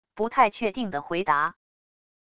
不太确定地回答wav音频
不太确定地回答wav音频生成系统WAV Audio Player